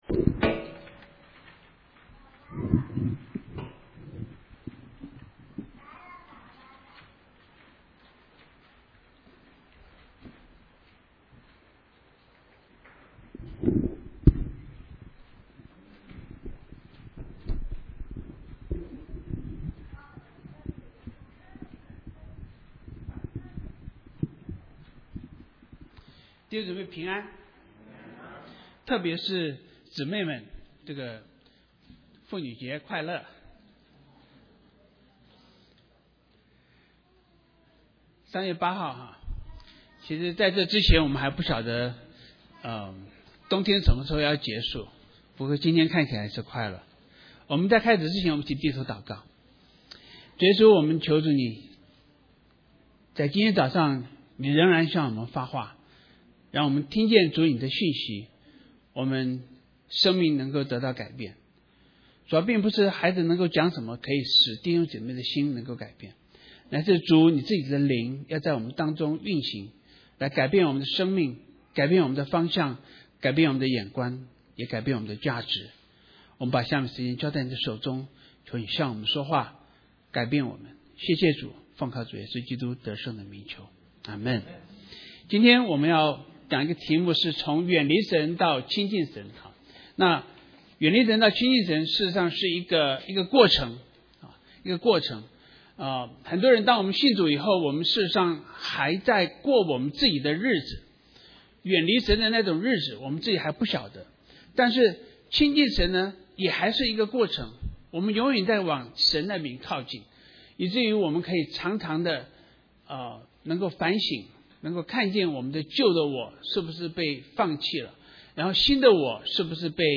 Mandarin Sermons